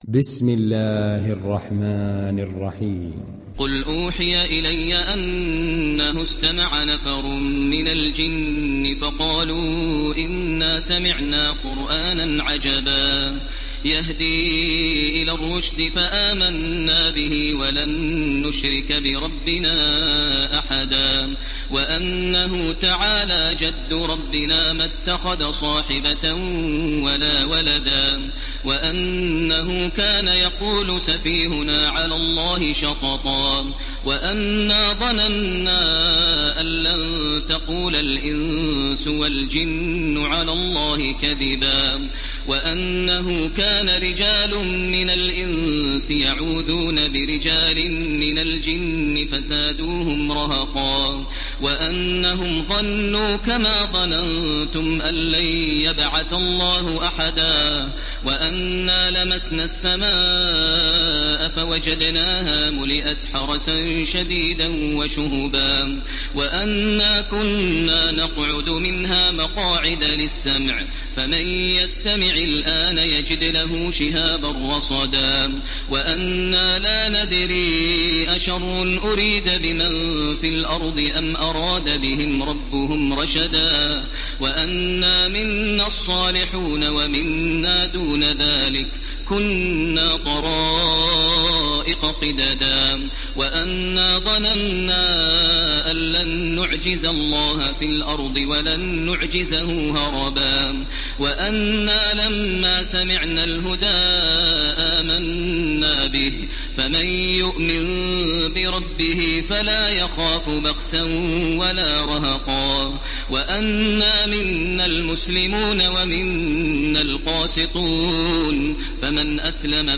Télécharger Sourate Al Jinn Taraweeh Makkah 1430